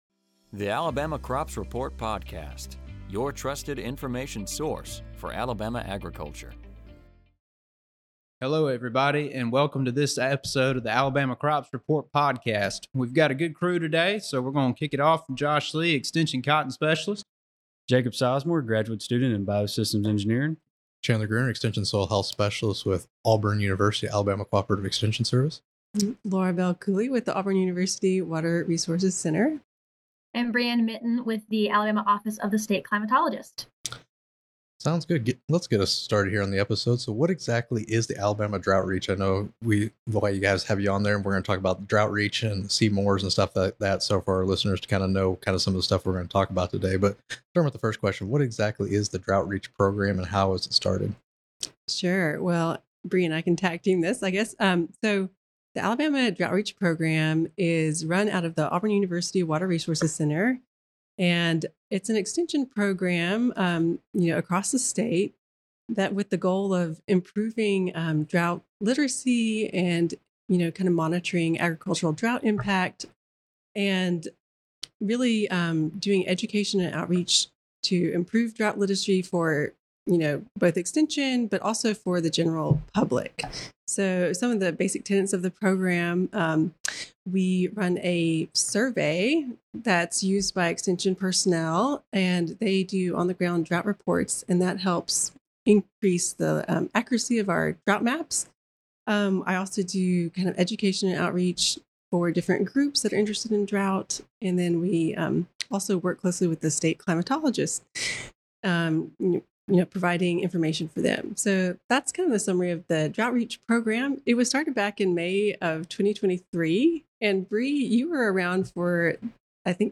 On this episode of the Alabama Crops Reports, hosts and special guests discuss the Alabama Drought Reach Program and ways listeners can contribute to drought data.